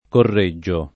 korr%JJo] top. (E.-R.) — ant. Coreggio [kor%JJo]: i signori della Mirandola, di Carpi e di Coreggio [i Sin’n’1ri della mir#ndola, di k#rpi e ddi kor%JJo] (Guicciardini) — pn. loc. con -e- aperta: cfr. Reggioil C., il pittore A. Allegri (1489-1534) — sim. il cogn. Da C.